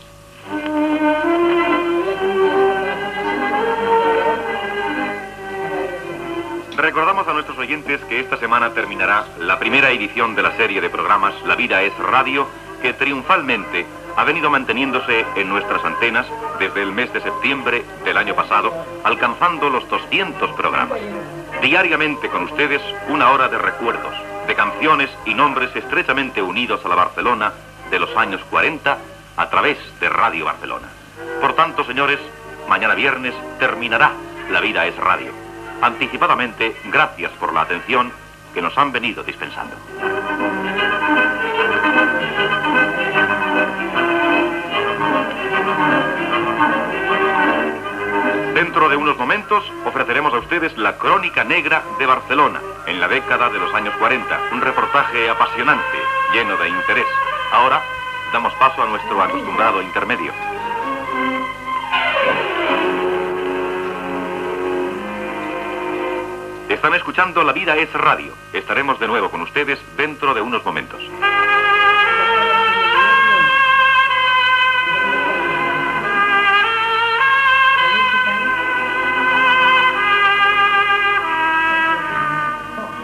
Anunci de la fi de la primera sèrie de programe, i pas a un tema musical
Entreteniment